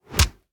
punch11.ogg